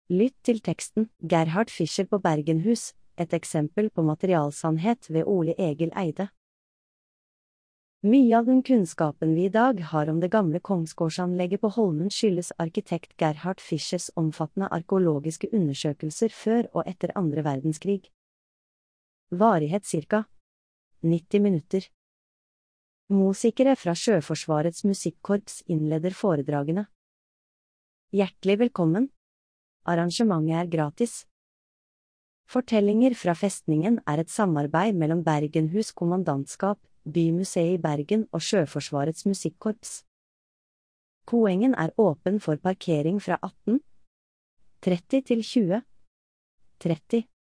Fortellinger fra Festningen er historiske foredrag.